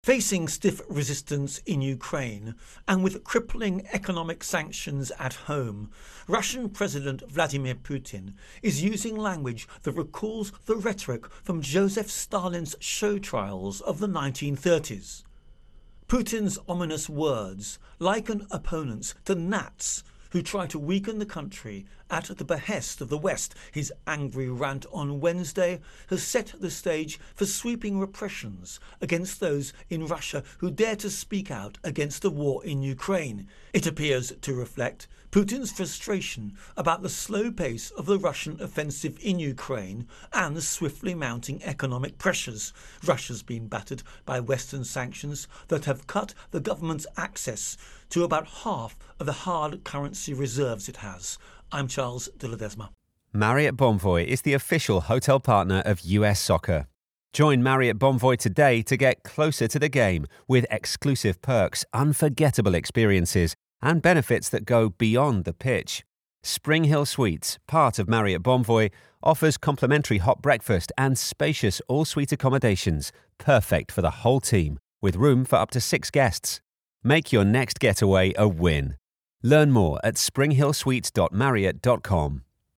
Russia Putin Crackdown Intro and Voicer